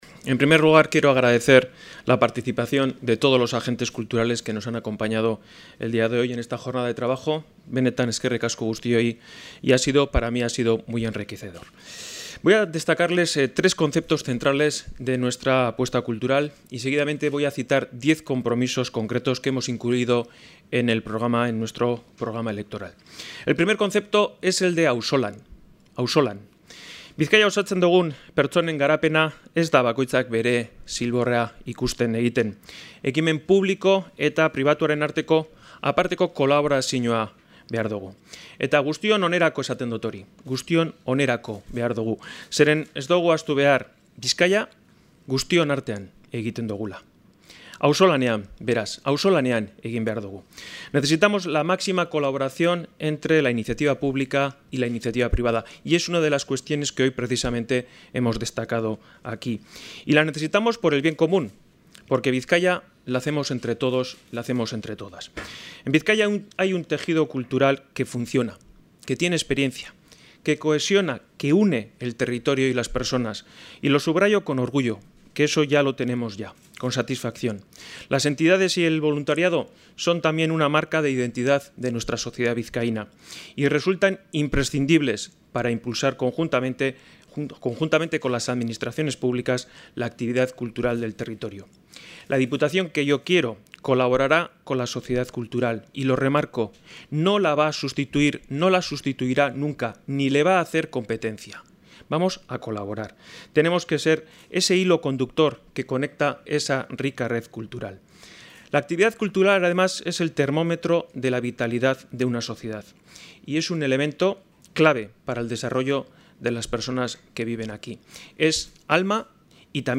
• Unai Rementeria en el encuentro sectorial sobre cultura 22/04/2015